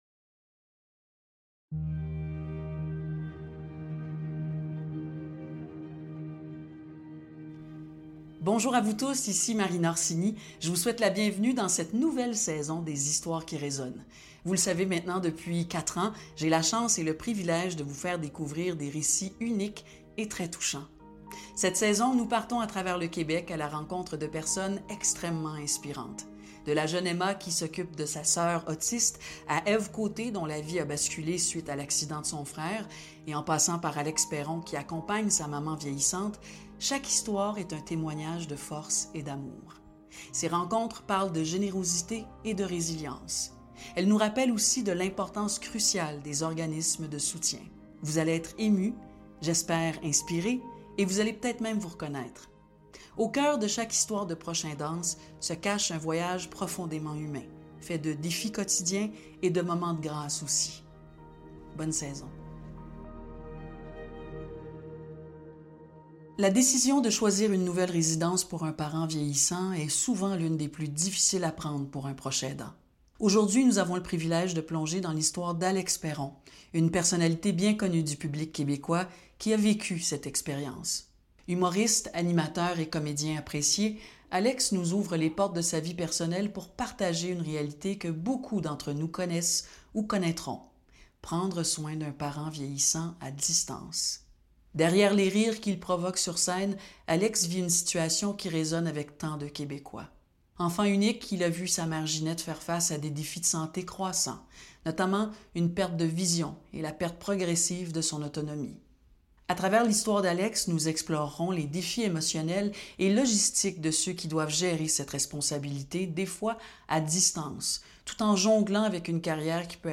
animé par Marina Orsini